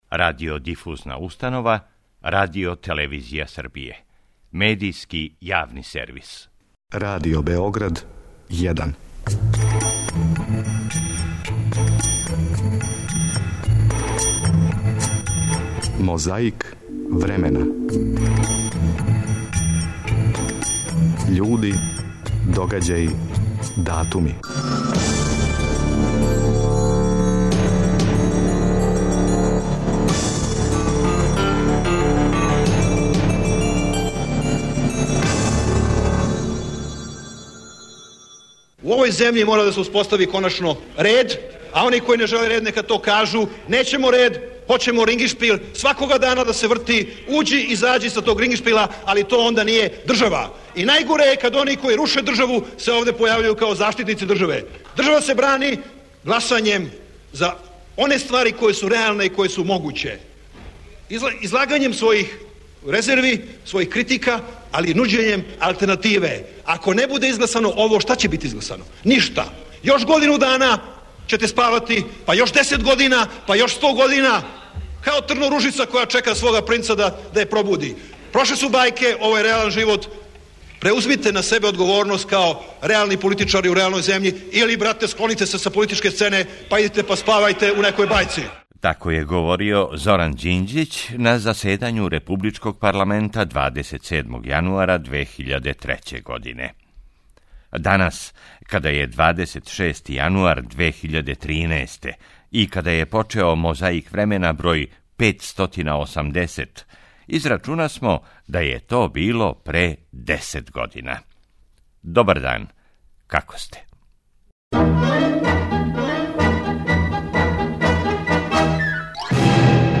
Пре десет година 27. јануара говорио је Зоран Ђинђић на заседању републичког парламента.
Некад такође било али, захваљујући техници, можемо да чујемо и како се говорило на Двадесетој седници ЦК СКЈ 1. фебруара 1989. године, на пример, како је говорио друг Азем Власи.